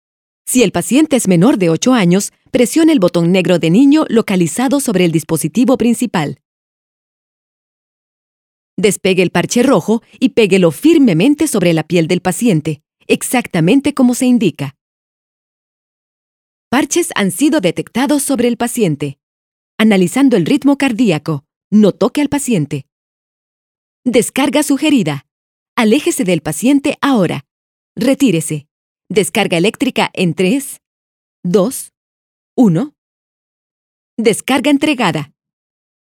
More than 25 years of experience recording commercials, e-learning, radio news, theatre, documentals, etc. in spanish latin american neutral.
I MAC with Pro Tools AKG 4000 microphone Pre amp Tube Bellari, DBX Compressor, Module Aphex
Sprechprobe: eLearning (Muttersprache):